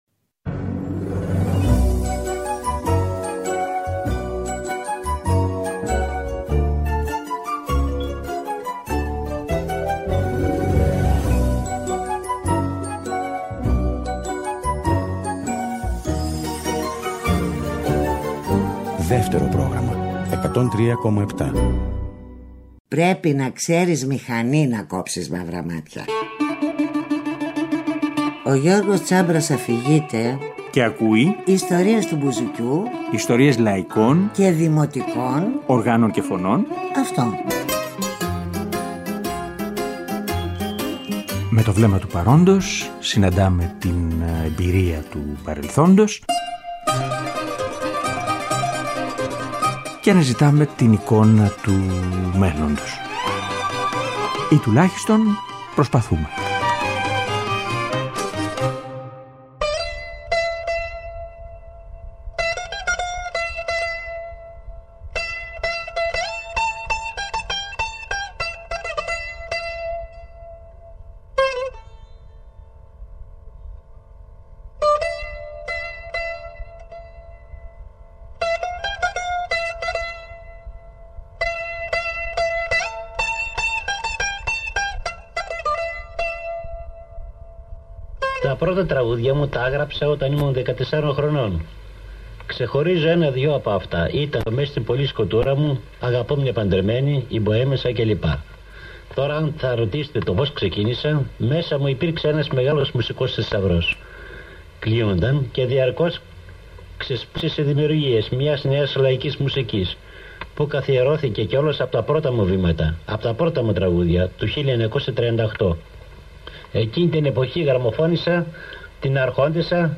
Από τον Τσιτσάνη στο σήμερα. Από τους Μικρασιάτες μαέστρους, στον Μάρκο και στον Τσιτσάνη. Μια συζήτηση